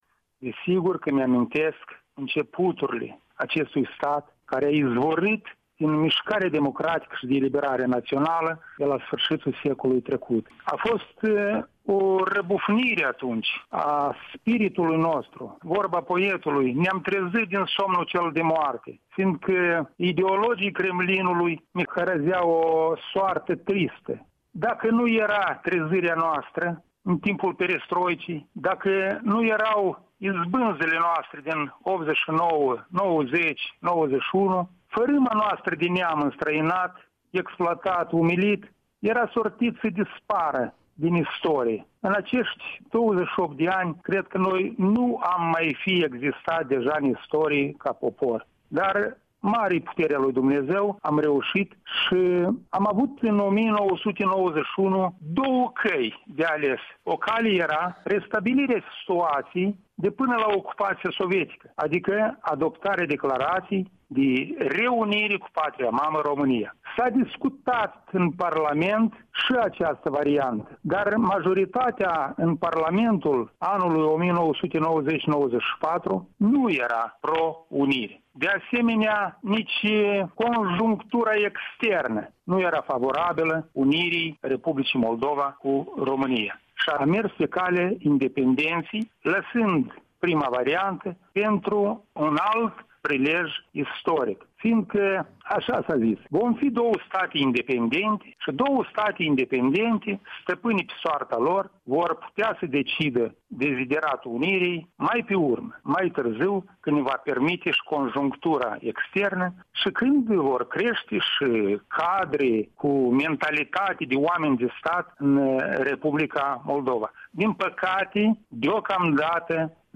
Un interviu cu un deputat în primul Parlament al Republicii Moldova, astăzi locotenent-colonel în rezervă, stabilit în Italia.
Un interviu cu deputatul în primul Parlament, Mihai Druță